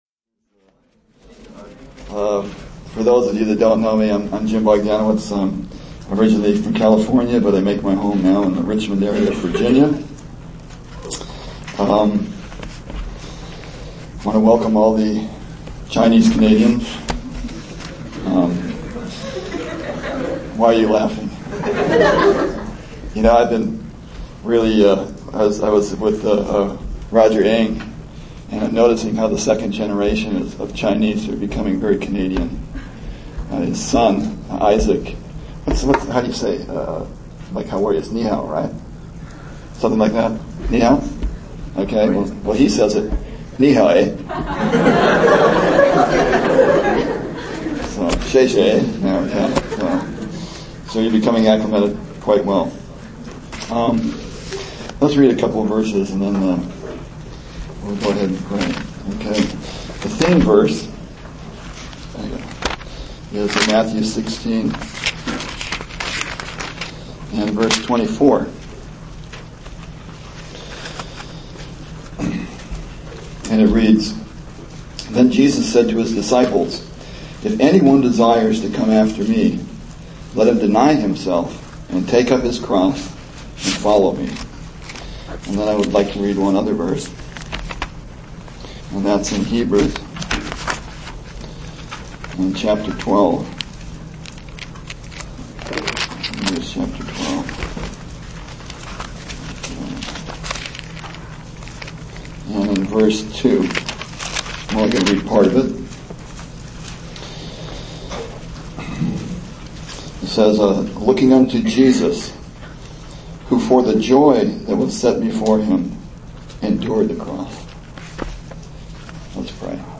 A collection of Christ focused messages published by the Christian Testimony Ministry in Richmond, VA.
Toronto Summer Youth Conference